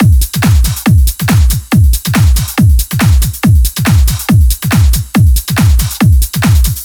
VFH2 140BPM Lectrotrance Kit 1.wav